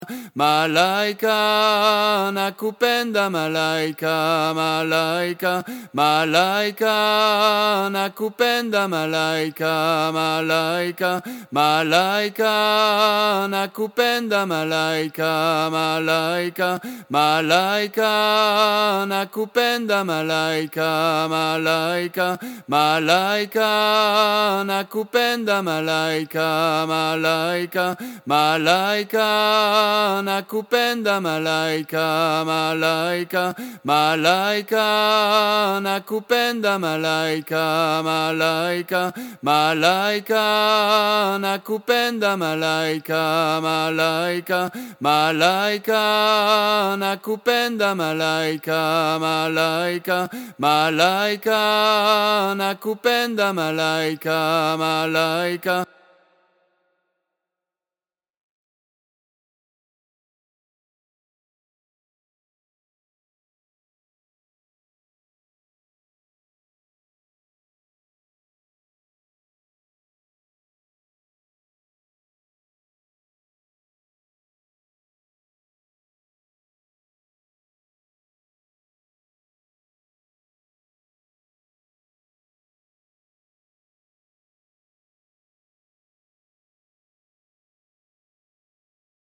La voix 2 et son playback :